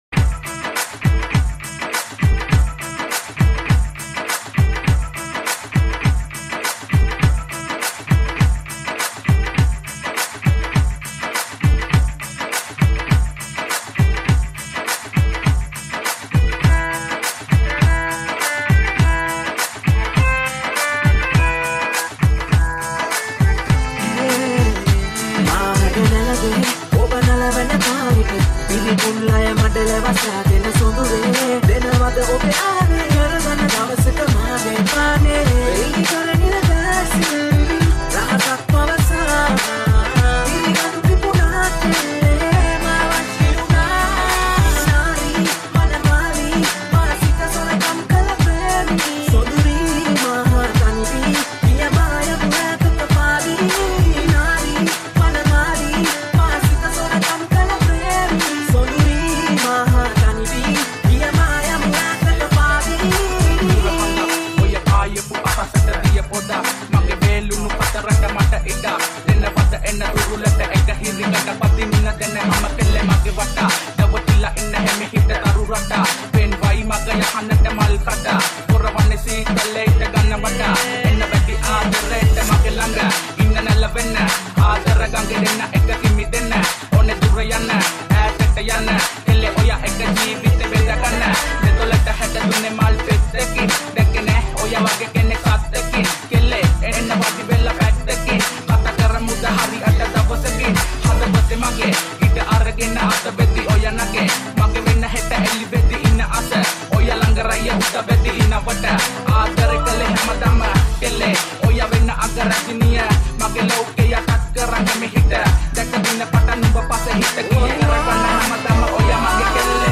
High quality Sri Lankan remix MP3 (4).